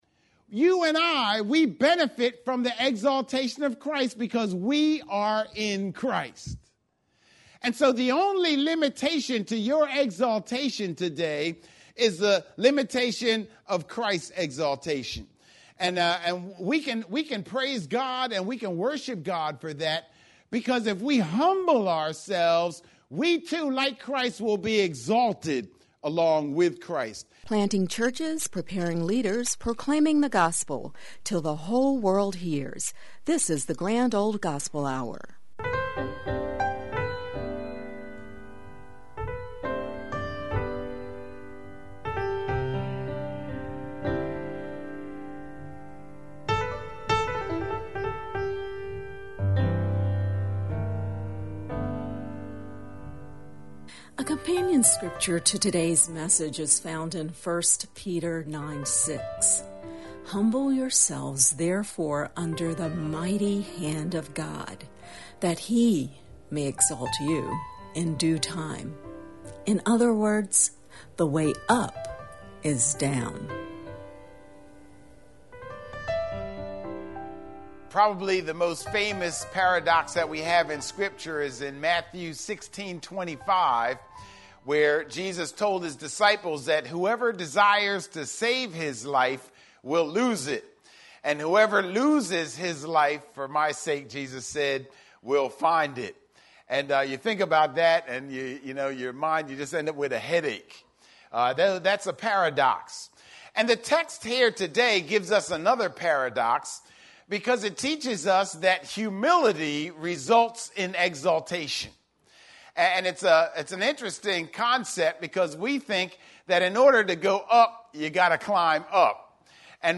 PDF Share this sermon: